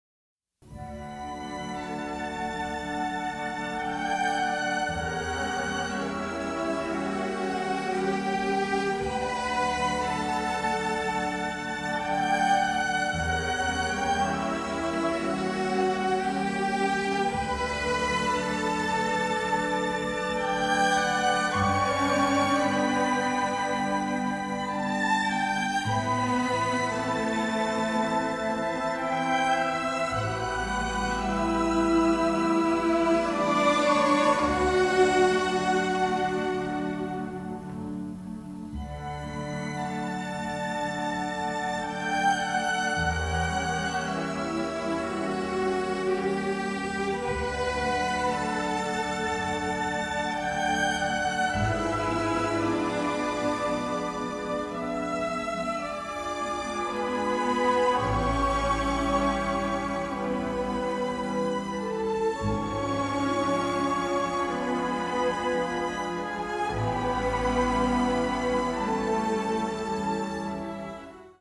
Analog Multi-Track Stereo Remix